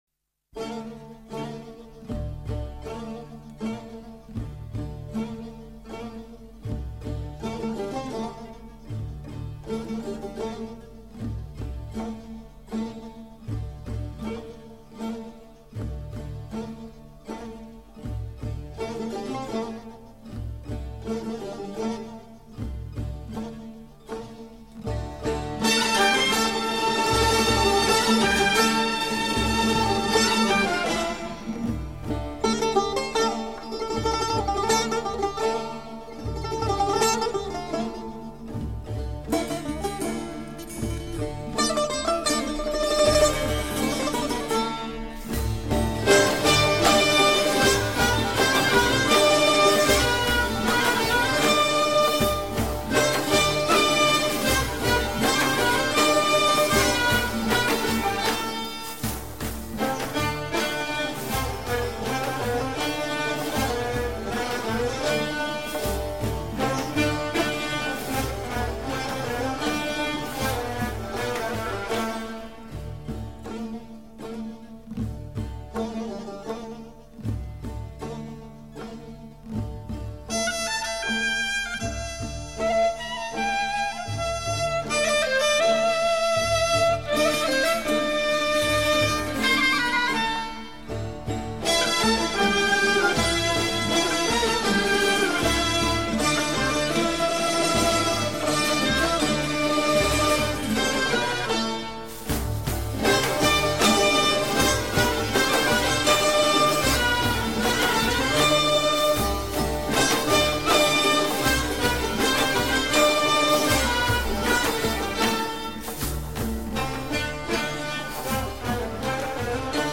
シュール旋法という古典の音楽体系を用いた作品です。